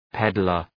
Προφορά
{‘pedlər}